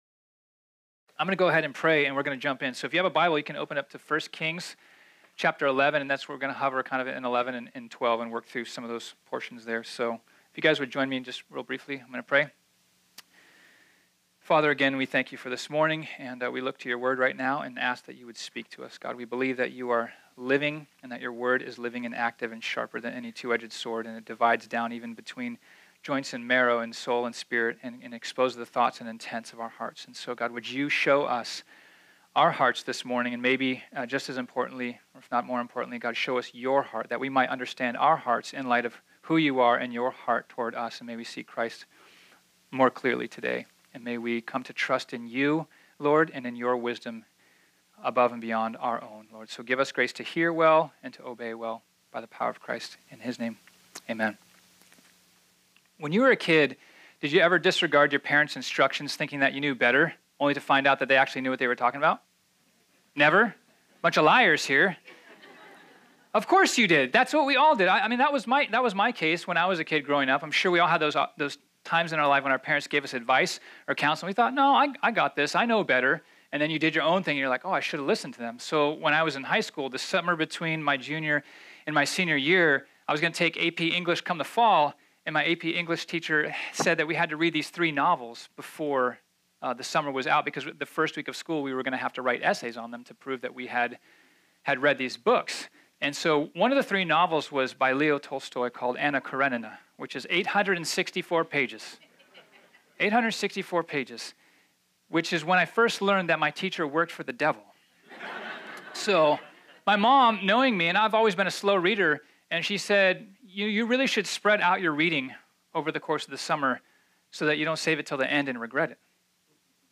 This sermon was originally preached on Sunday, July 1, 2018.